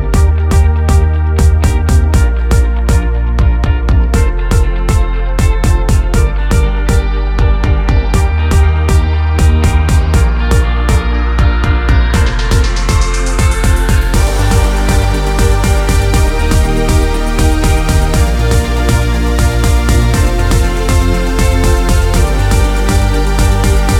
no Backing Vocals R'n'B / Hip Hop 3:48 Buy £1.50